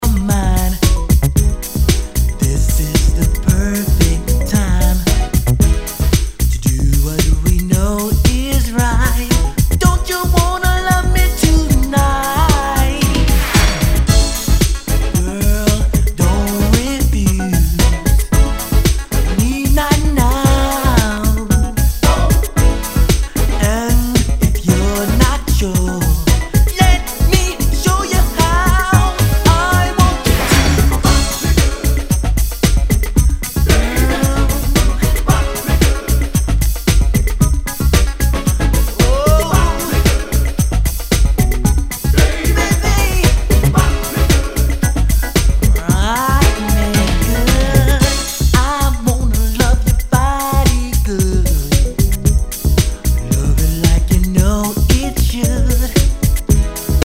HIPHOP/R&B
R&Bクラシック！